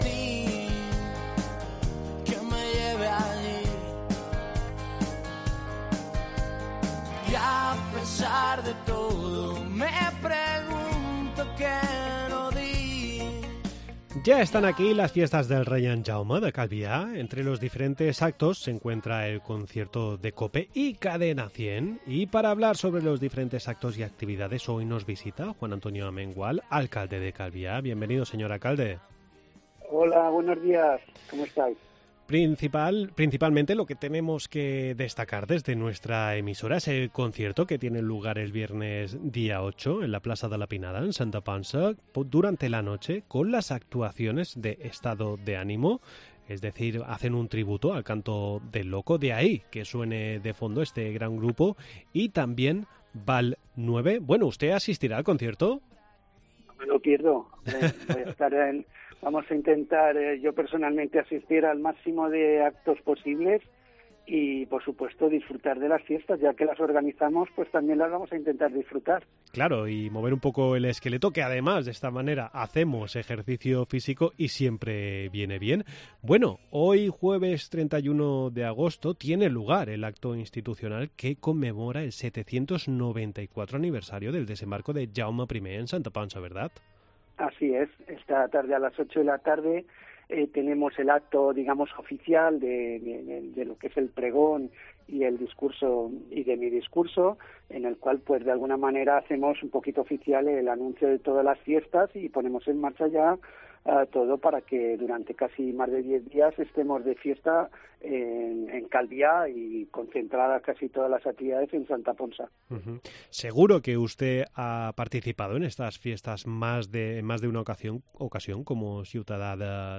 AUDIO: Hablamos con Juan Antonio Amengual, alcalde de Calviá, sobre el concierto que organizan Cope y Cadena 100 y del resto de actividades de las...